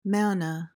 PRONUNCIATION: (MAH-nuh) MEANING: noun: Power, energy, force, or prestige.